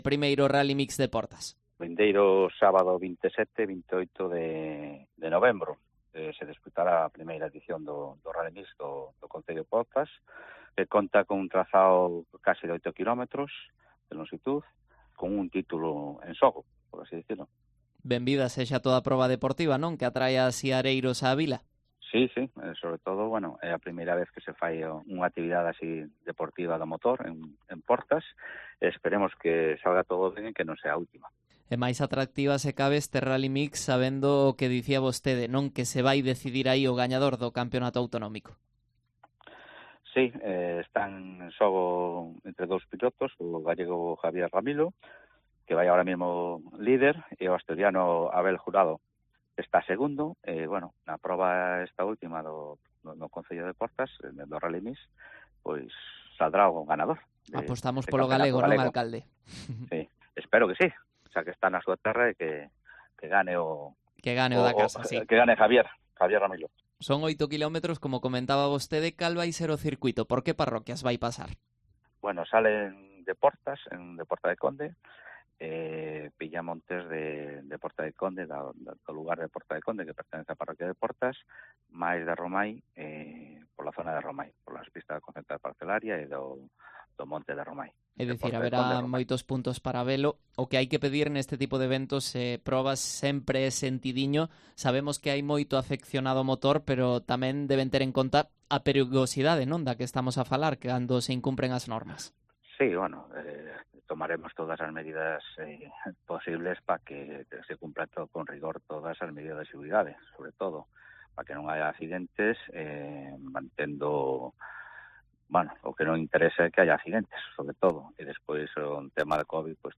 Entrevista Ricardo Martínez, alcalde de Portas